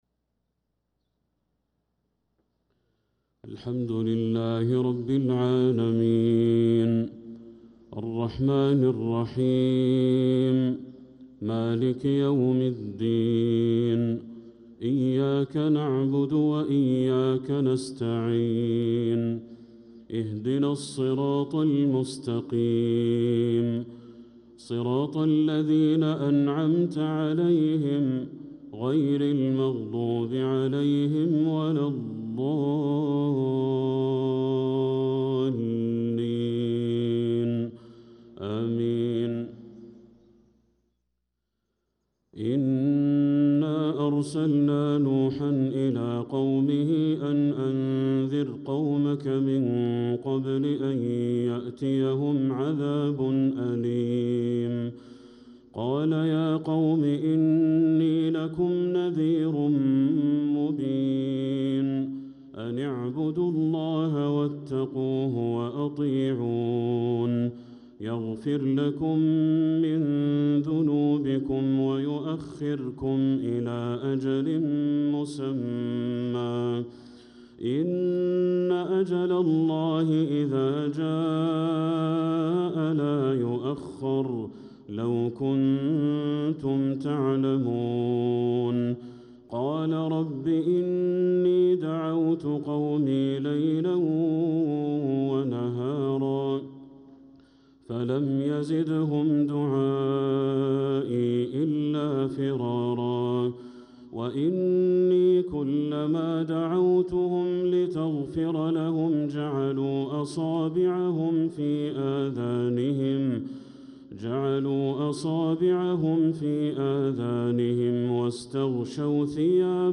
صلاة العشاء للقارئ بدر التركي 27 ربيع الآخر 1446 هـ